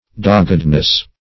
Doggedness \Dog"ged*ness\, n.